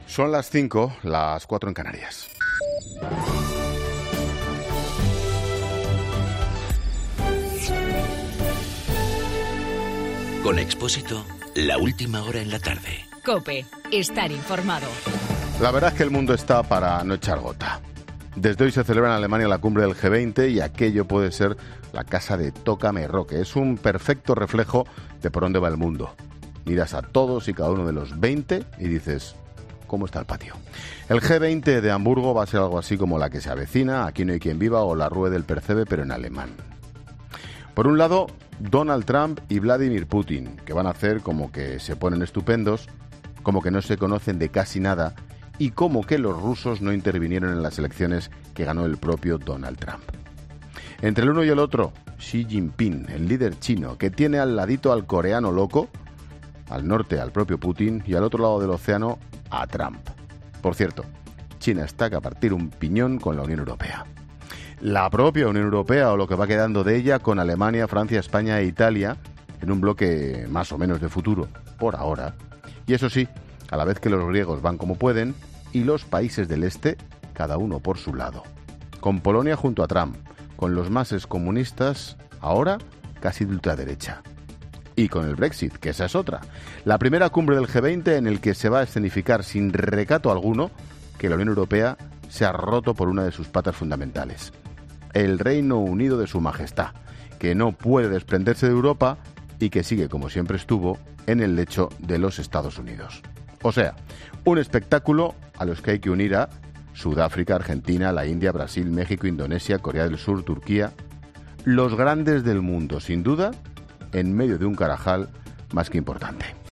AUDIO: Ángel Expósito en su monólogo de las 17h habla sobre la cumbre del G-20 y las tensiones entre Putin y Trump.